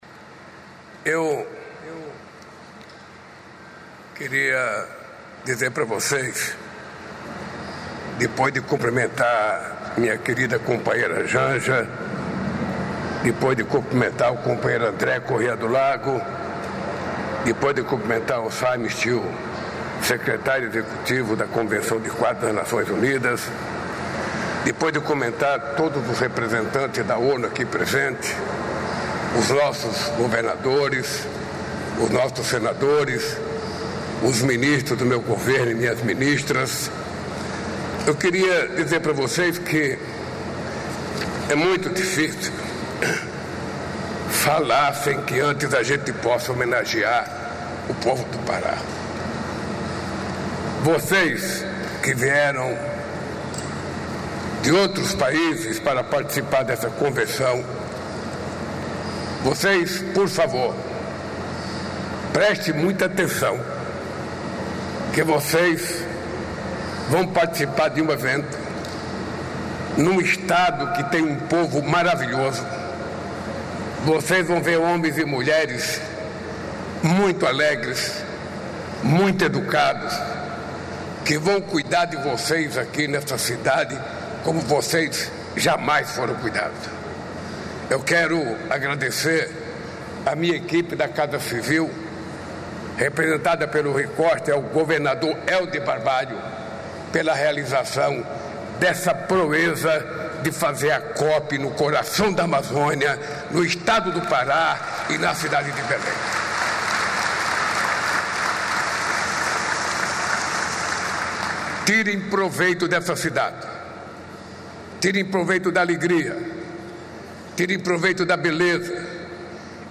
Ferramenta inédita criada em parceria com o Movimento Brasil Competitivo, observatório reúne dados, estratégias e avanços para enfrentar os entraves à competitividade nacional. Ouça o discurso completo do presidente Lula no lançamento durante o 14º Encontro Nacional da Indústria, nesta quarta-feira (27), em Brasília.